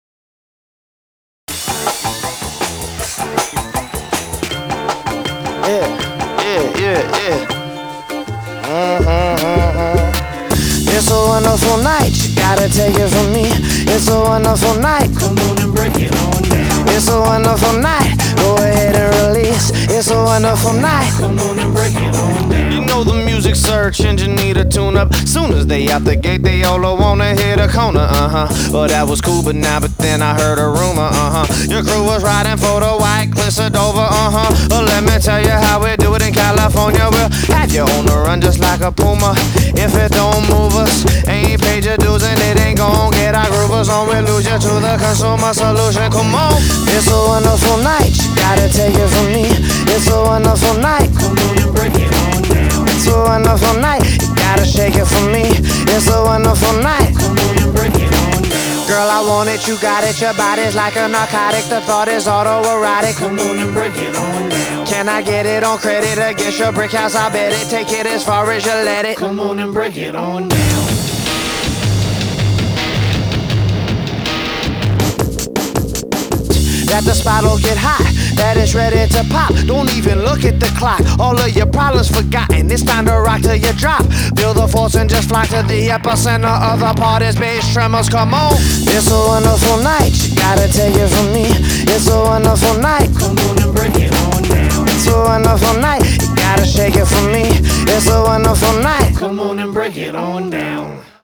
BPM160
Audio QualityPerfect (High Quality)